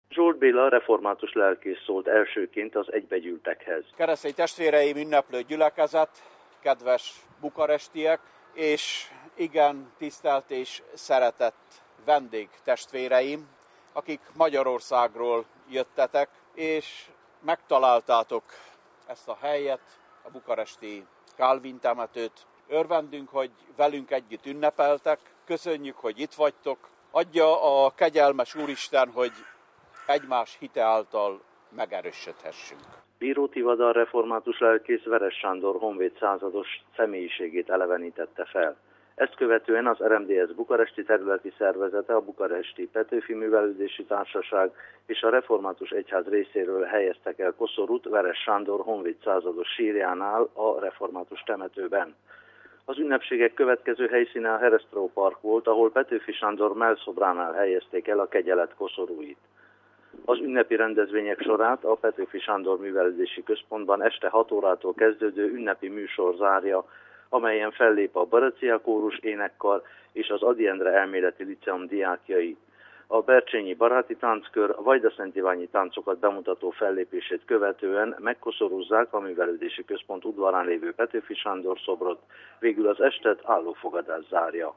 Bukarestben hagyományosan Veress Sándor honvéd százados, a református egyház egykori főgondnoka sírjánál tartott megemlékezéssel és koszorúzással kezdődtek a nemzeti ünnepnek szentelt rendezvények.